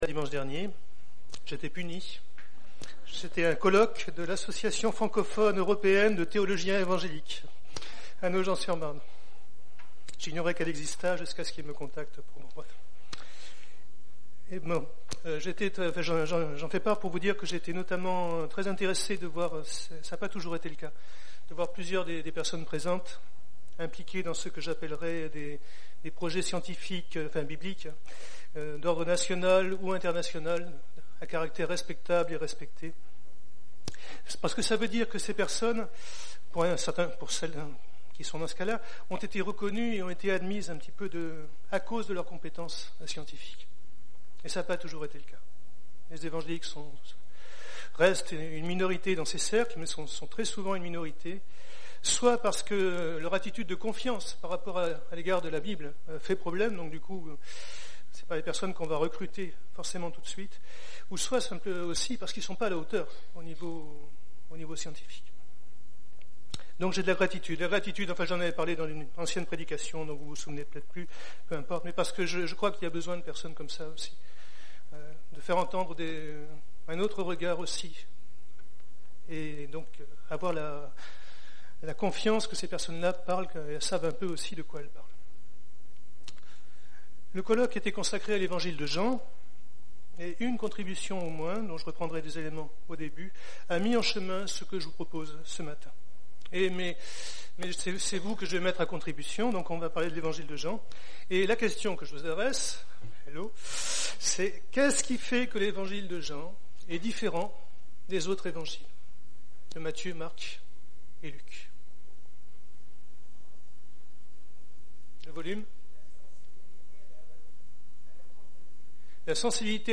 Il y a des problèmes d’enregistrement sur la fin du message … le son « tremble » …